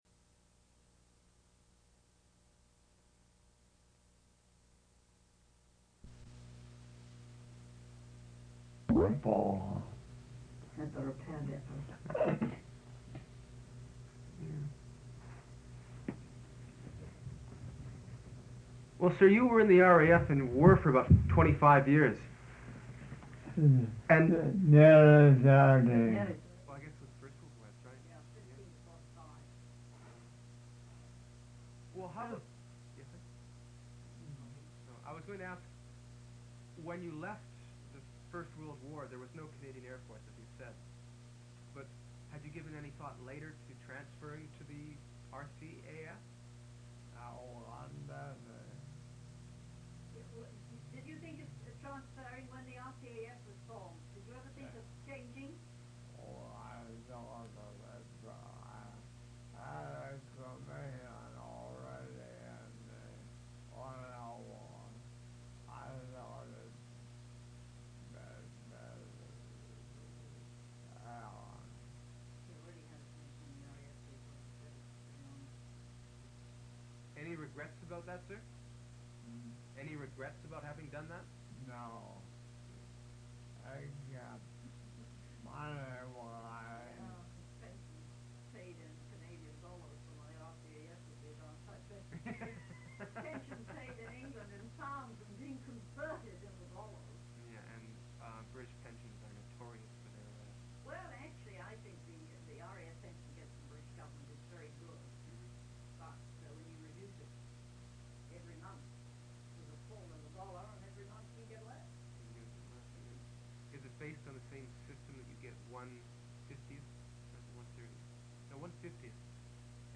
Interview took place on September 10, 1983.